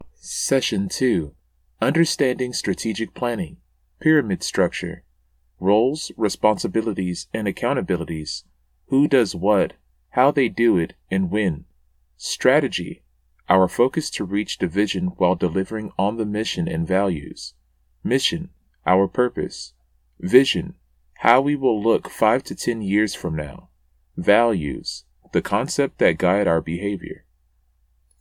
Voice Over Work